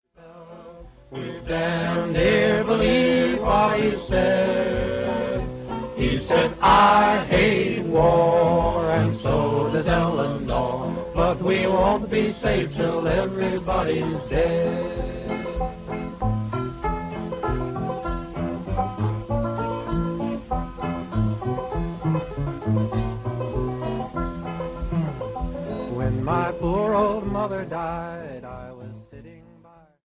lead vocal